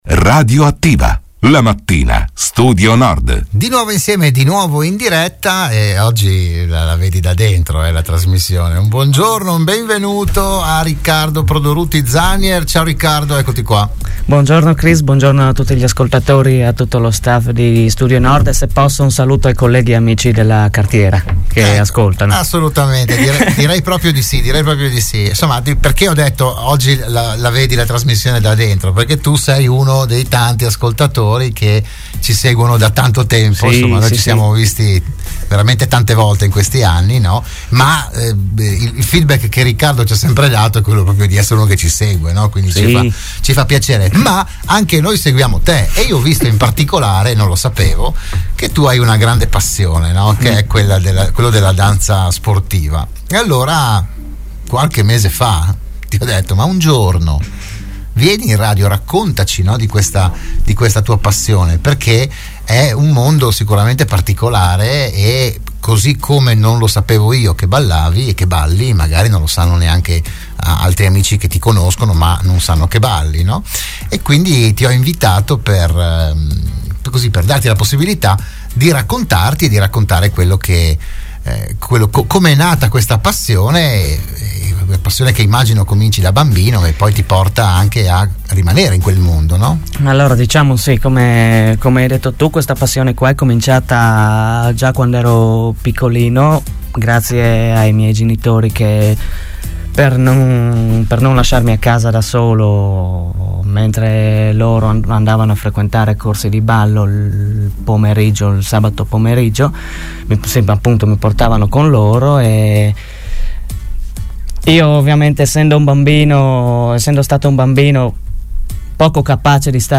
Oggi il giovane carnico è stato ospite della trasmissione di Radio Studio Nord "RadioAttiva"